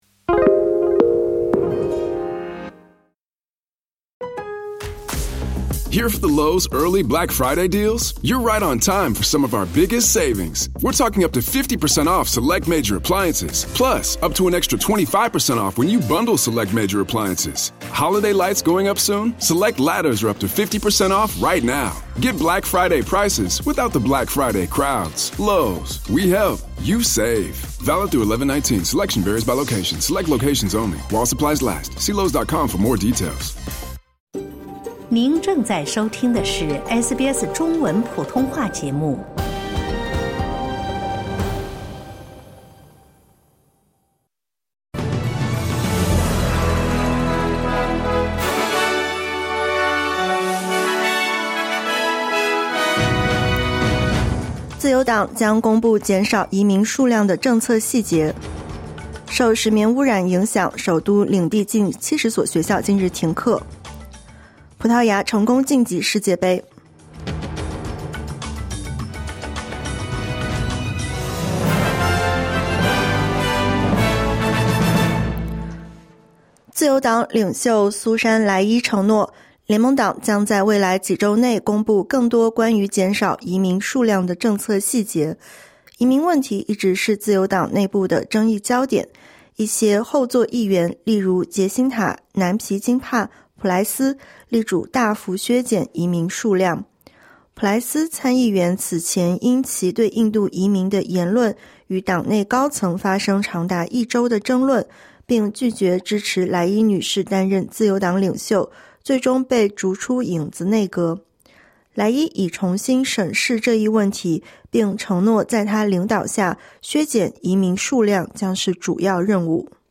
SBS 早新闻（2025年11月17日）